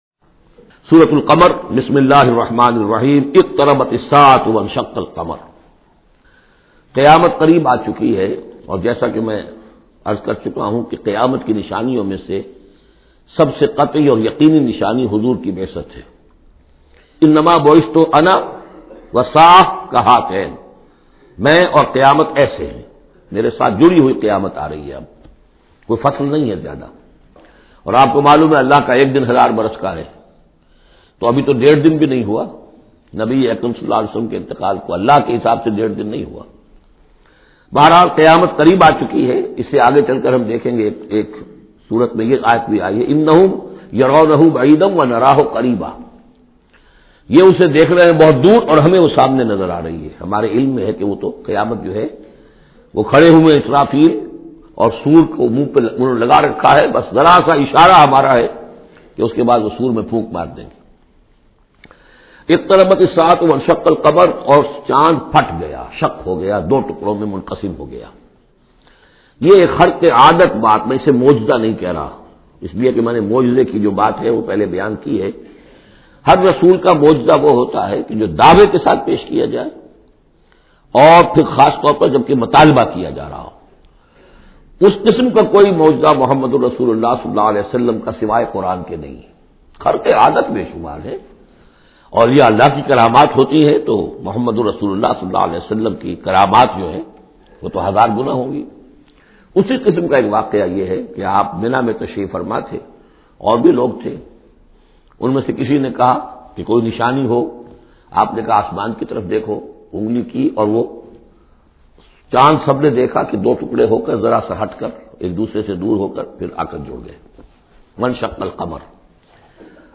Surah Qamar Tafseer by Dr Israr Ahmed